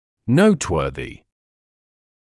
[‘nəutˌwɜːðɪ][‘ноутˌуёːзи]заслуживающий внимания